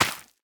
minecraft / sounds / item / plant / crop3.ogg